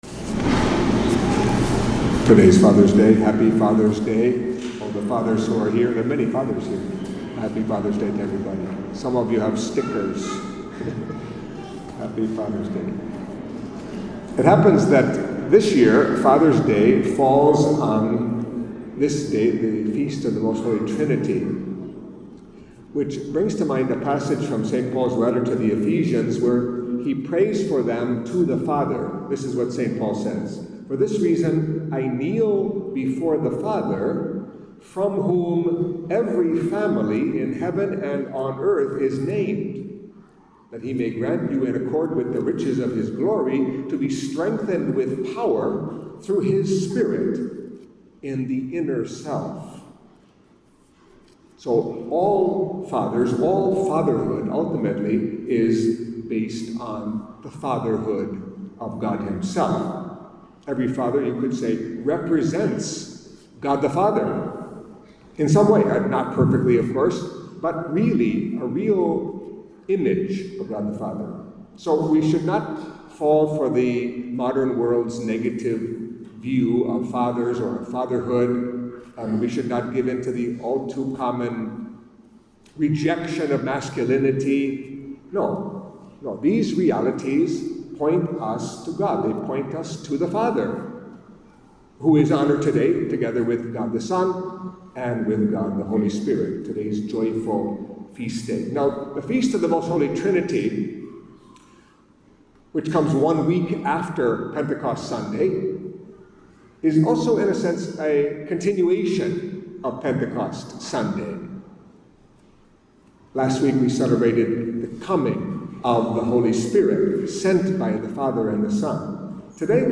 Catholic Mass homily for Solemnity of the Most Holy Trinity